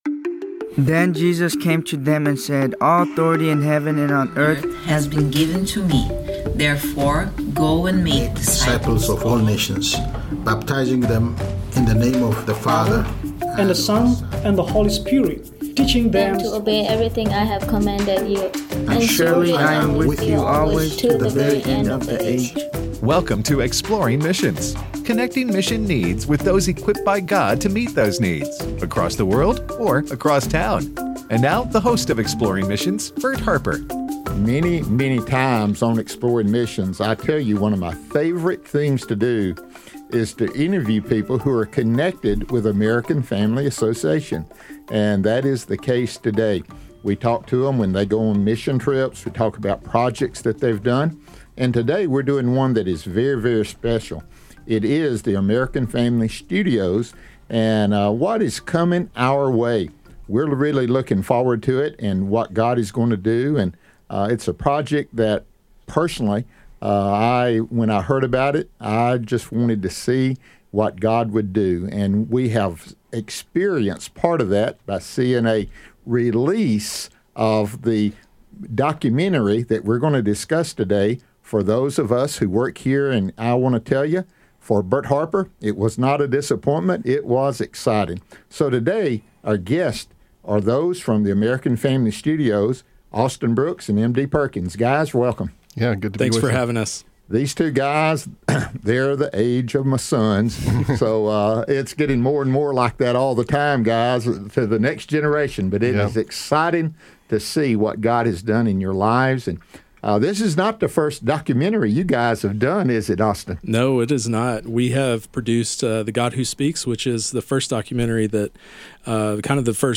Culture Warrior, Don Wildmon and the Battle for Decency: A Conversation